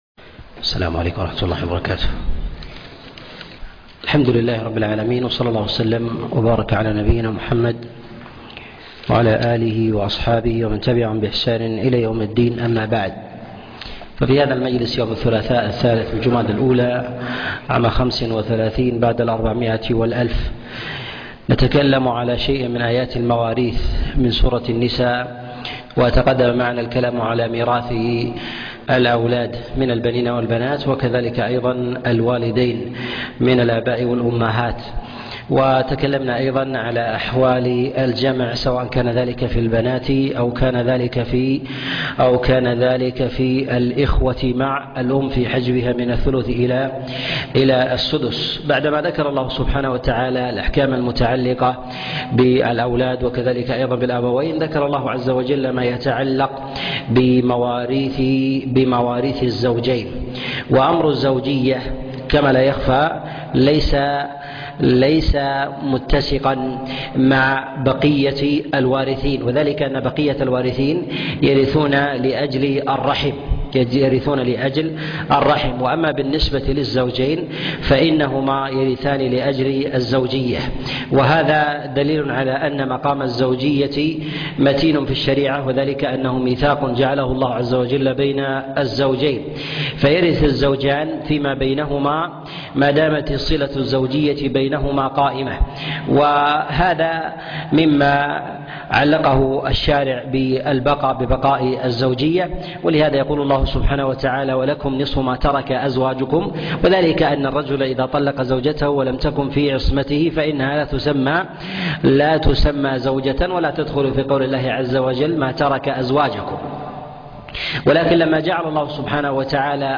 تفسير سورة النساء 6 - تفسير آيات الأحكام - الدرس الستون